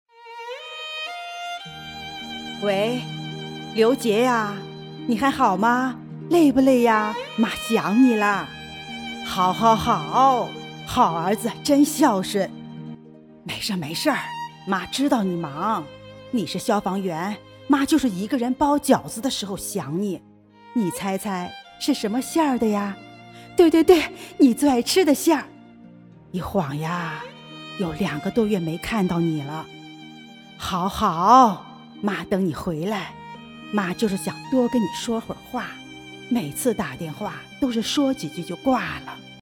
15 女国145_动画_角色_65岁妈妈打电话样音煽情 女国145
女国145_动画_角色_65岁妈妈打电话样音煽情.mp3